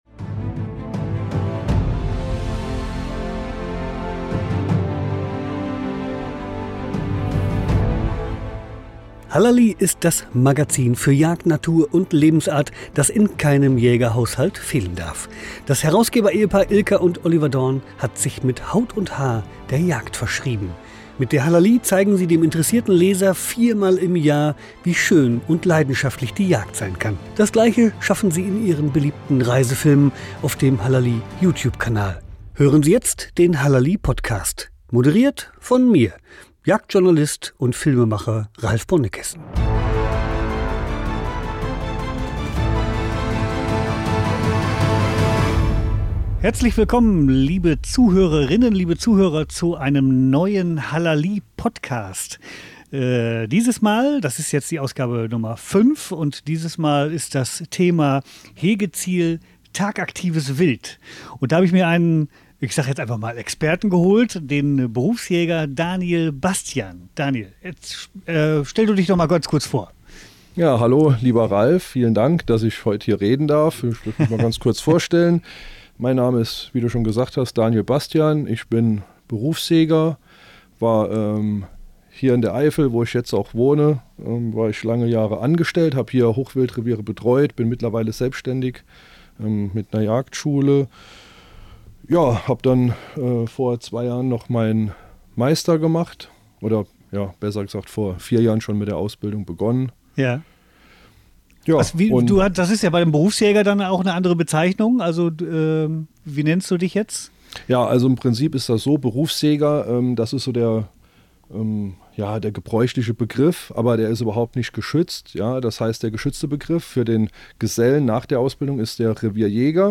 Der bekannte Jagdfilmer und Journalist
mit dem Berufsjäger